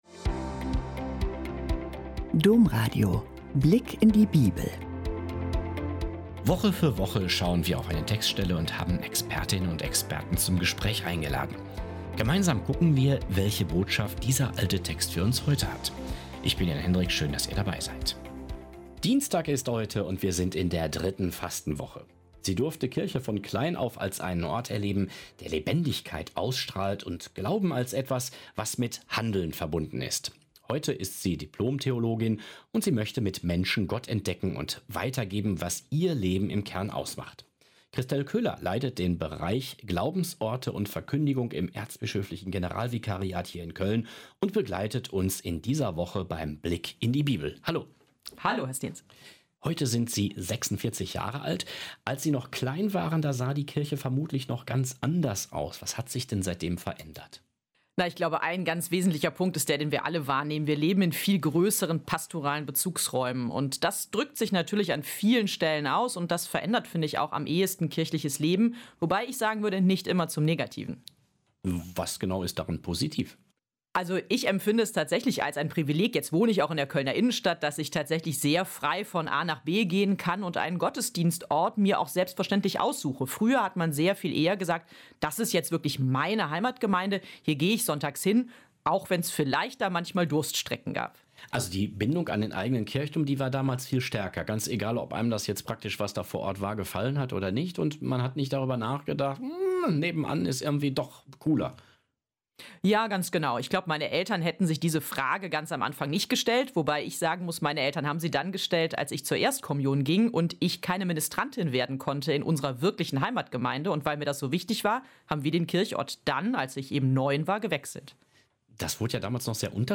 "Vergebung ist grenzenlos" - Gespräch